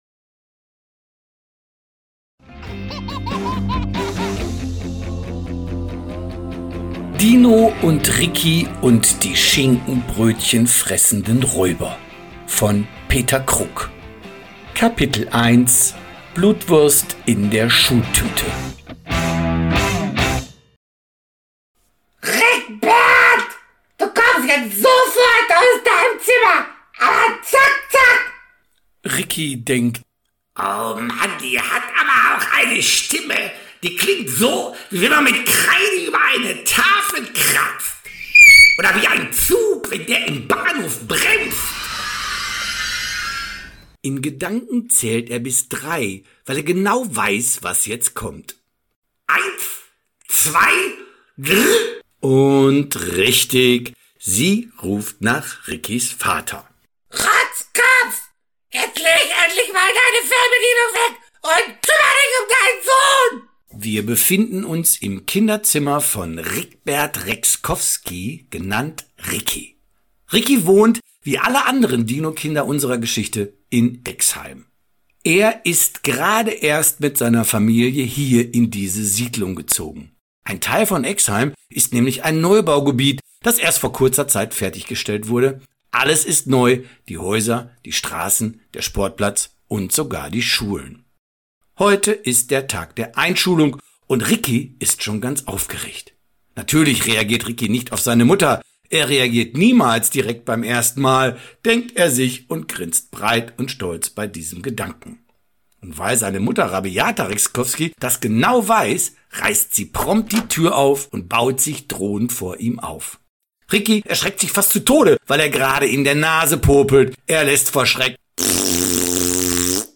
Dino + Ricky – Kinderbuch & Hörspiel: Räuber, Action & Spaß